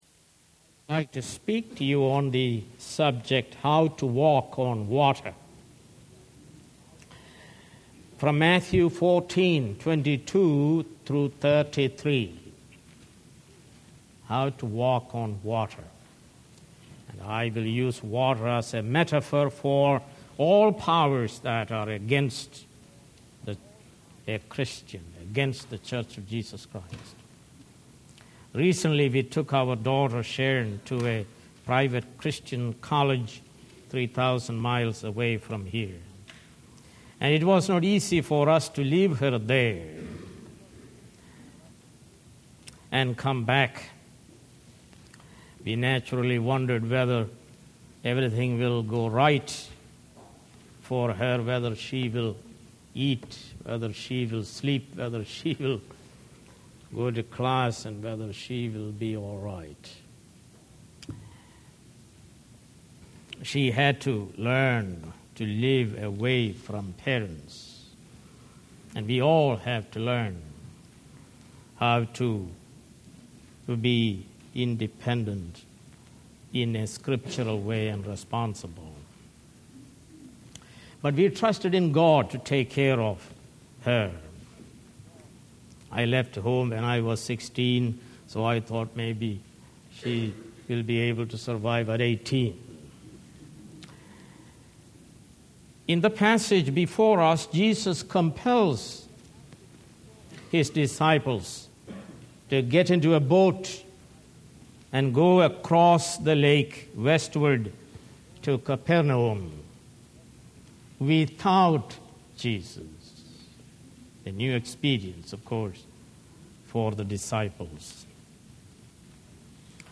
More Sermons